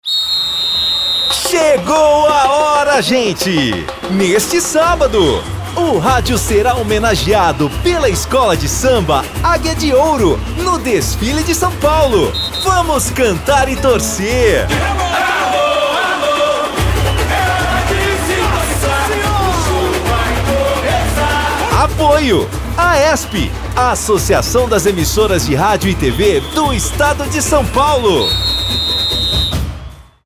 Sob a coordenação da AESP (Associação das Emissoras de Rádio e TV do Estado de São Paulo), foram distribuídos spots de rádio com uma contagem regressiva para o desfile da Águia de Ouro, que ocorrerá neste sábado.